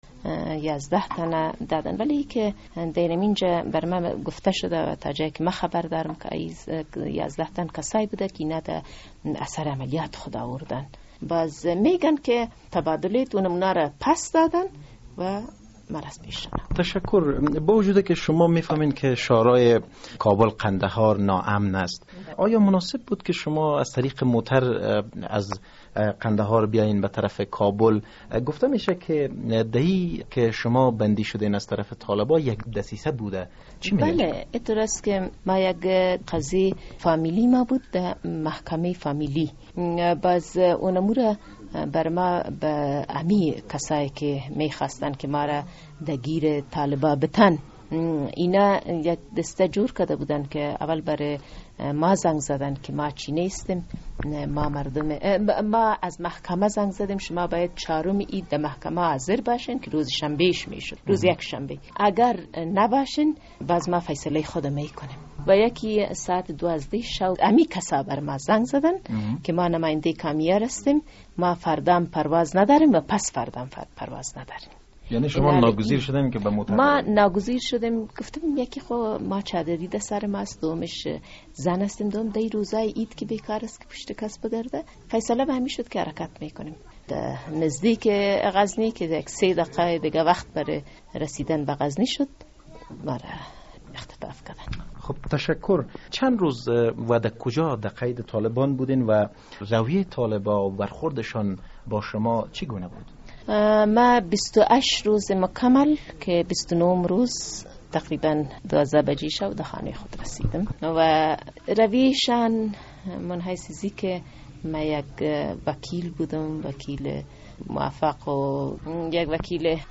مصاحبه: چگونگی اختطاف و رهایی فریبا کاکر از نزد طالبان